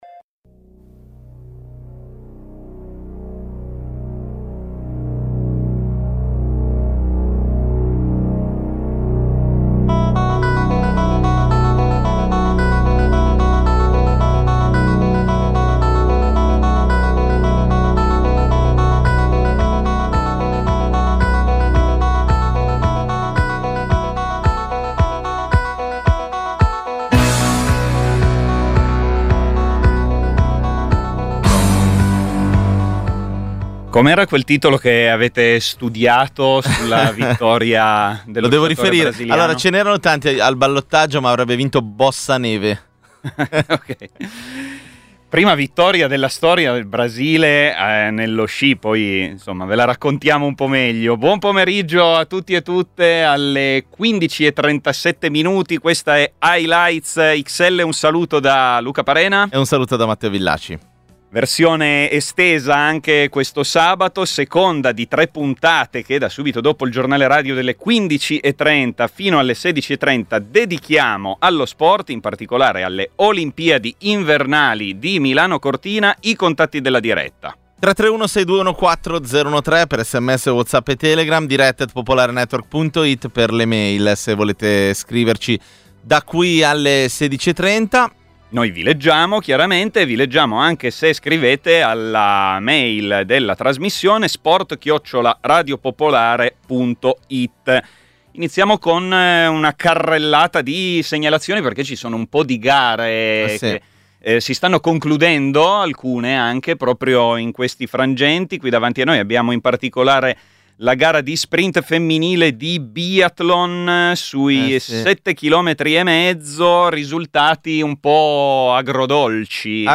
Gli aggiornamenti in diretta da studio sulle gare ai Giochi olimpici di Milano-Cortina, le imprese e le questioni che hanno tenuto banco nell'arco...